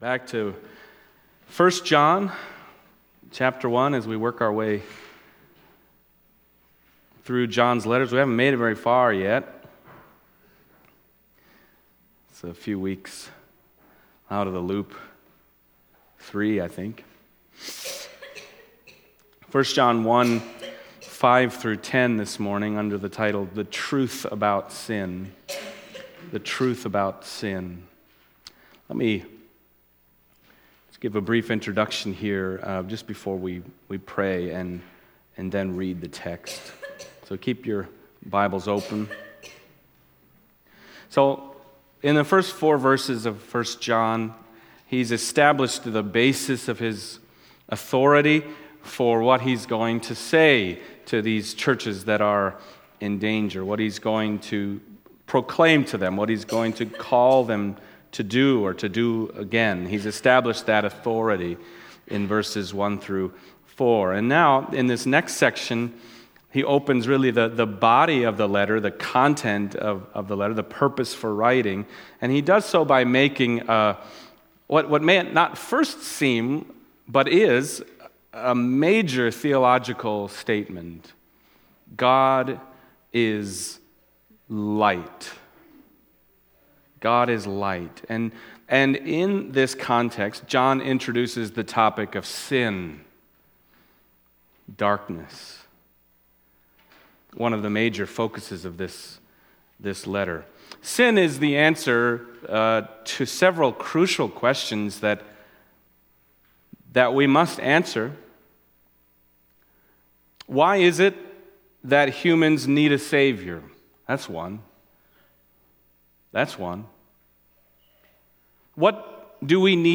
Passage: 1 John 1:5-10 Service Type: Sunday Morning